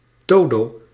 Ääntäminen
Ääntäminen Tuntematon aksentti: IPA: /ˈdoːdoː/ Haettu sana löytyi näillä lähdekielillä: hollanti Käännös Konteksti Ääninäyte Substantiivit 1. dodo kuvaannollinen 2. sleep UK US 3. nighty night Suku: m .